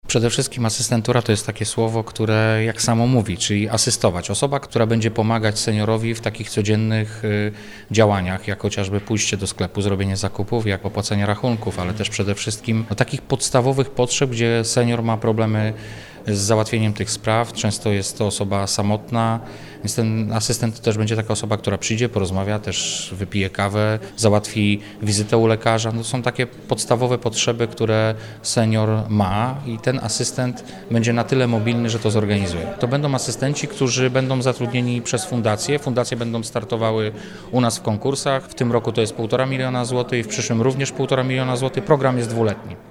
Chcemy przeznaczyć dotacje na wsparcie osób starszych, często samotnych, poprzez obecność wykwalifikowanych asystentów – mówi Wojciech Bochnak, wicemarszałek Województwa Dolnośląskiego.
01_wicemarszalek.mp3